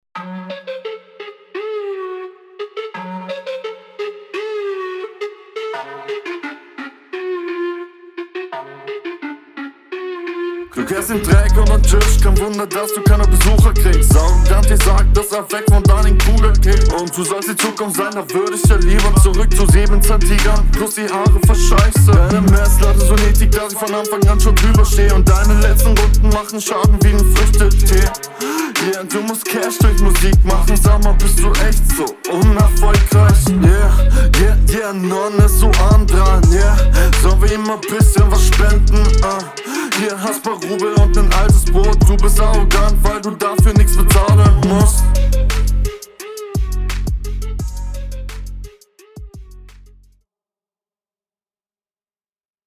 Auch ganz cool gerappt aber kommst mMn nicht an den Gegner ran.